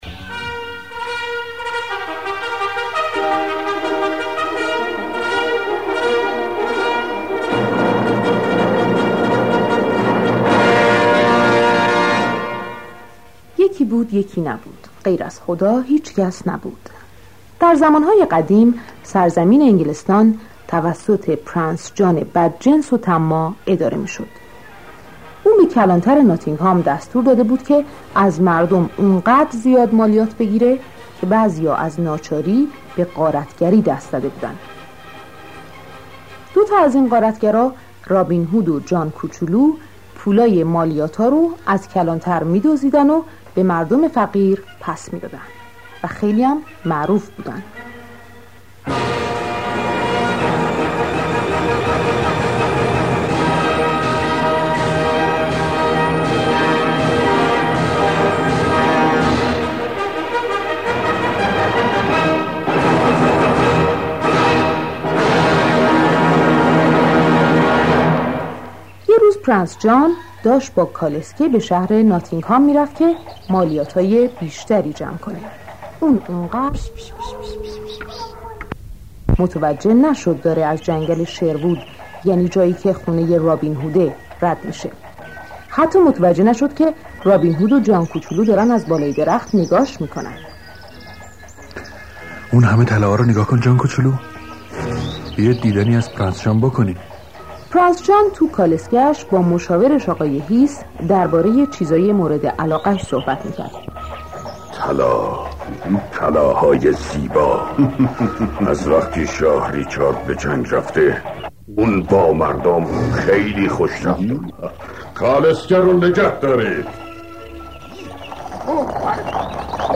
قصه کودکانه صوتی رابین هود
قصه-کودکانه-صوتی-رابین-هود.mp3